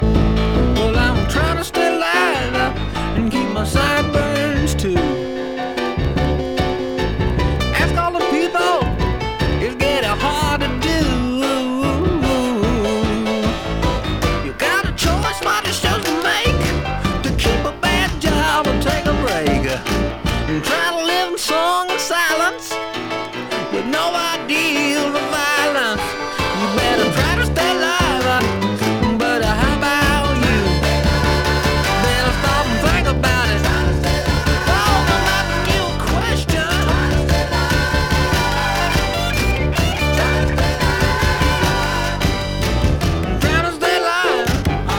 Rock, Psychedelic, Symphonic　USA　12inchレコード　33rpm　Stereo